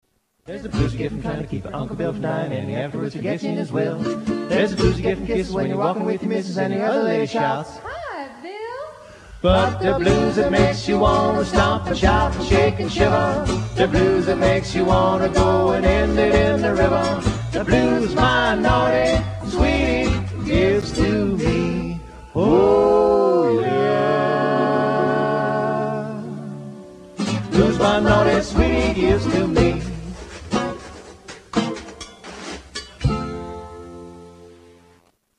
and one of the Midwest's finest old-time, good-time bands.